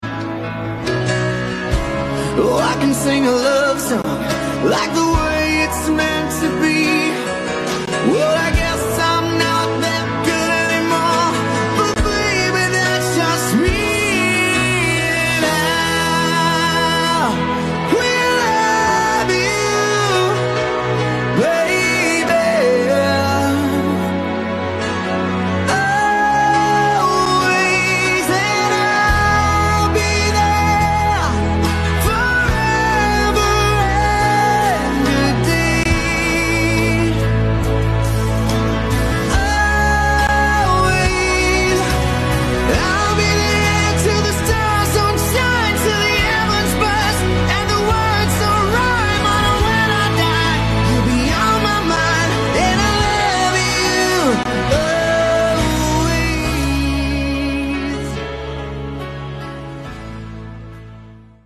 Genre: Rock, Power Ballad 4.